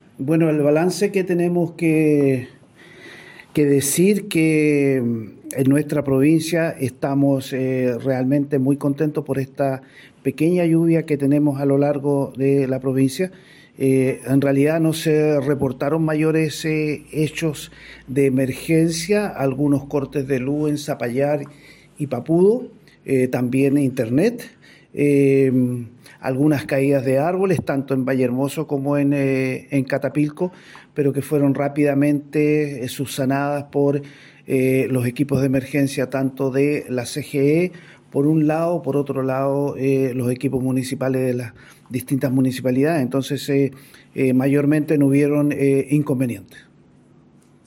El delegado presidencial provincial de Petorca, Luis Soto Pérez, entregó detalles de los efectos de este sistema frontal:
Delegado-Presidencial-Provincial-de-Petorca-por-lluvia.mp3